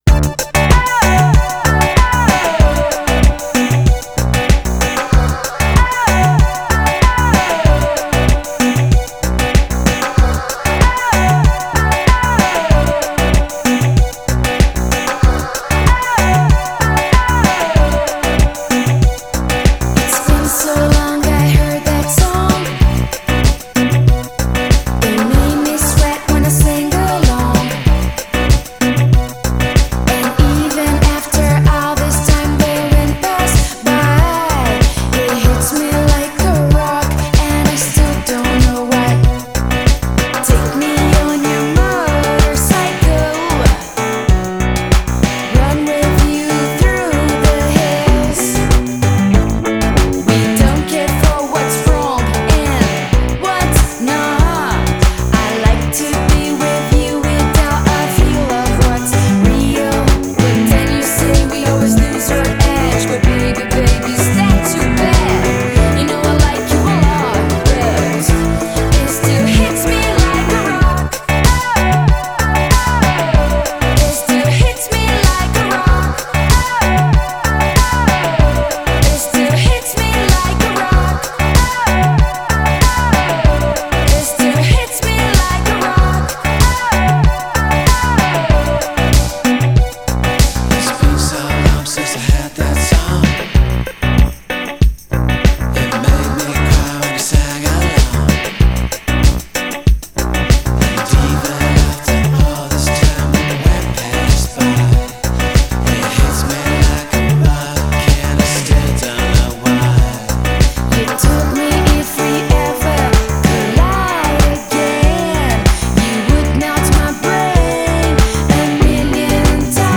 features guest vocals